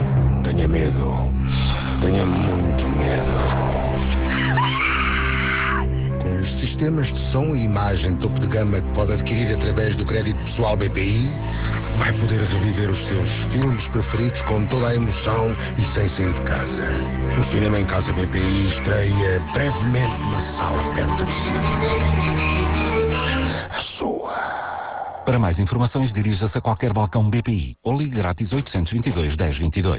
Esta campanha arrancou dia 12 de Novembro, conta com 3 spots diferentes de 30 segundos e encontra-se em exibição na RFM, TSF, Rádio Comercial e Rádio Clube Português sendo o montante investido, a preço de tabela, até 16 de Novembro de 73.496 €.